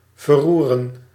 Ääntäminen
Ääntäminen US
IPA : /ə.ˈraʊz/